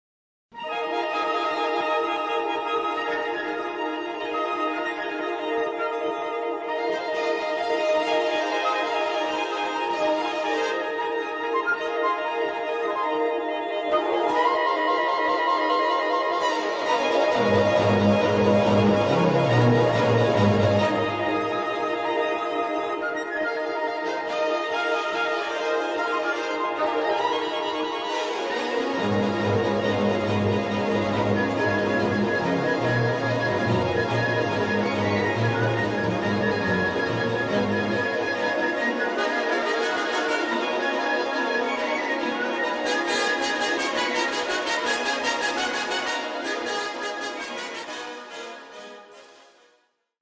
scrittura irregolare, asimmetrica, poliritmica e politonale
un frammento dal balletto